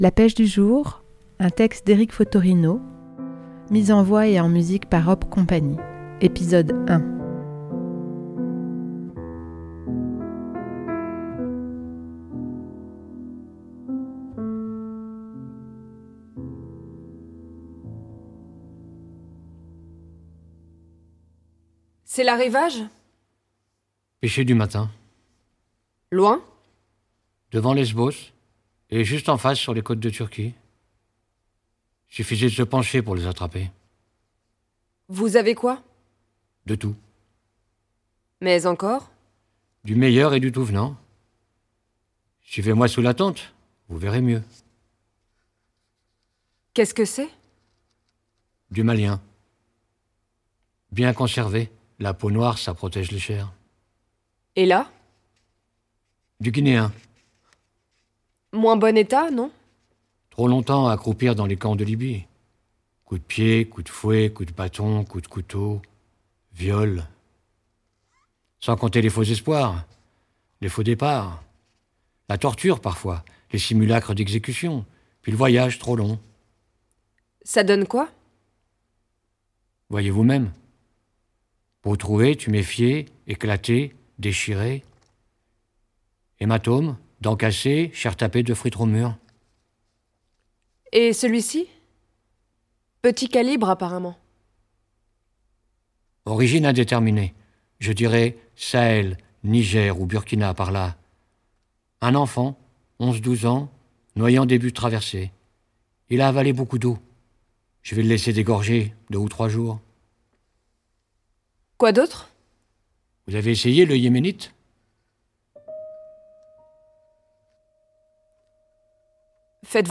Hop Cie met en scène et en musique le texte coup de poing d'Eric Fottorino "La pêche du jour".